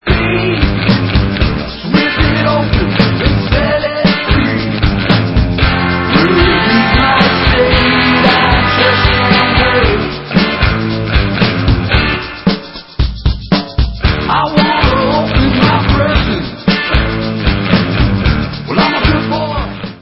americká indie-rocková kapela